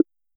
back-button-hover.ogg